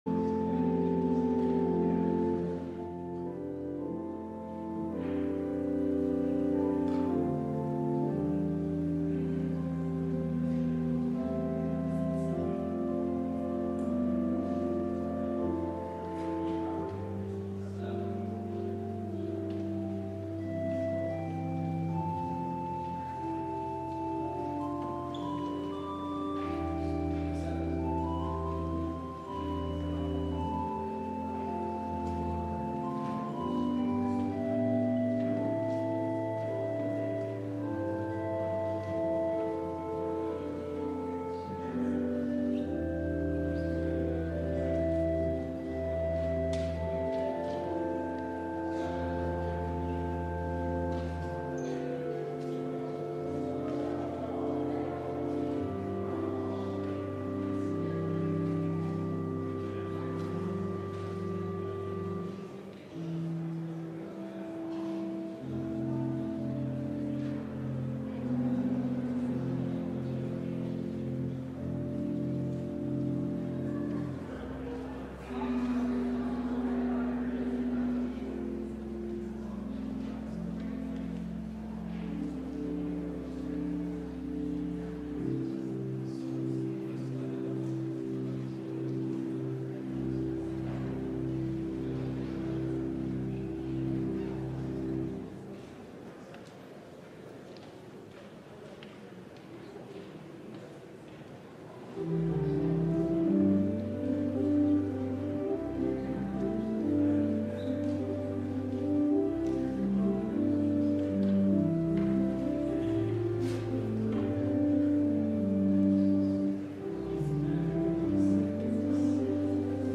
LIVE Midday Worship Service - The Image of the Invisible God: The Incomprehensible God